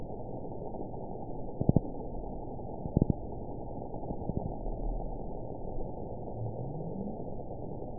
event 922380 date 12/30/24 time 05:27:36 GMT (4 months ago) score 9.56 location TSS-AB04 detected by nrw target species NRW annotations +NRW Spectrogram: Frequency (kHz) vs. Time (s) audio not available .wav